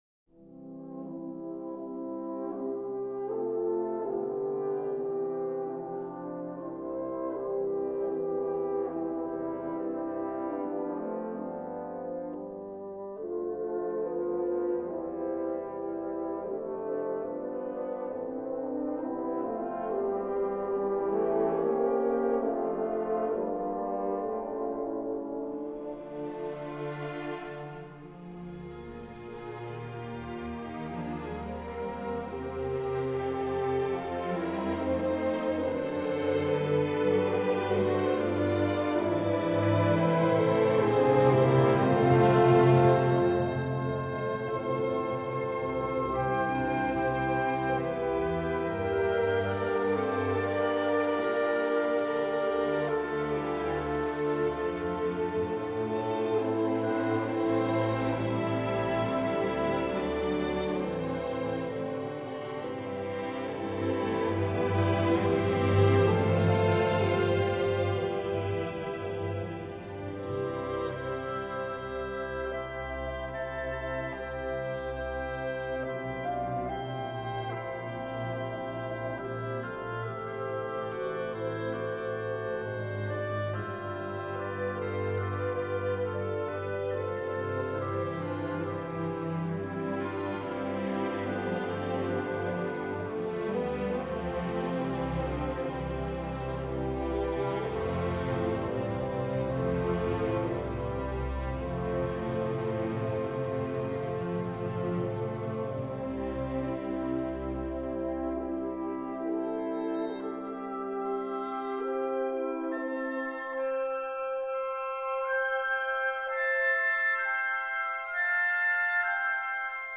Es ist eine ältere Aufnahme, und sie dient nur dazu, einen Eindruck von der Musik zu vermitteln.
haensel-and-gretel-ouvertuere.mp3